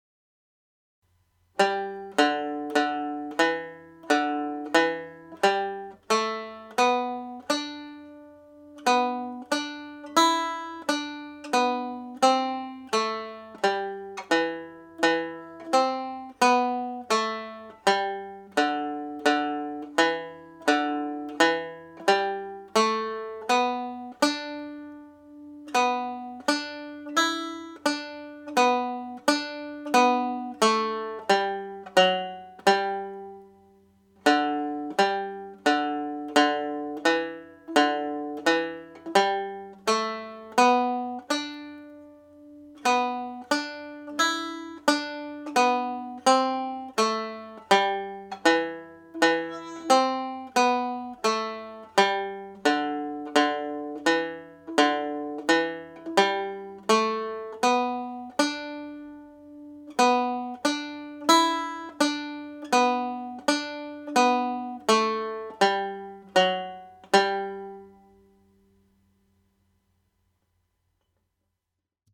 It was originally written in the key of D major but I have transcribed the music in the key of G major.
first part played slowly